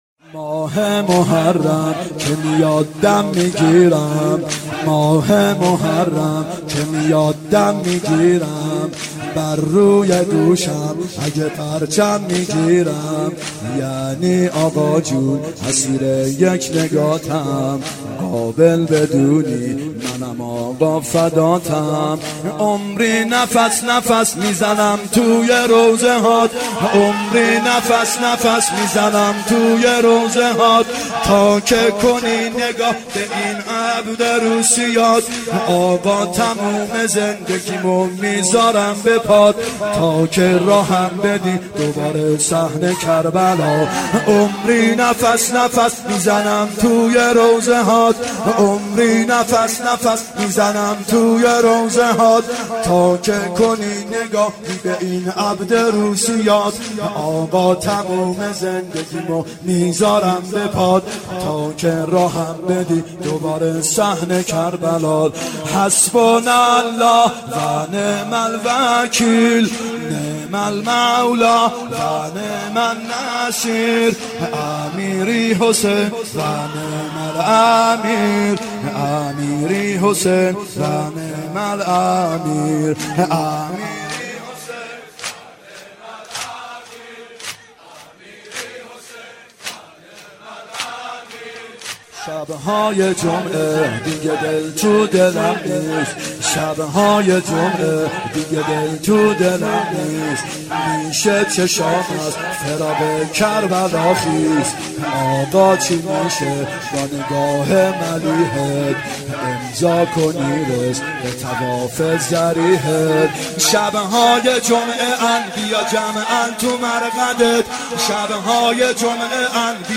محرم 91 ( هیأت یامهدی عج)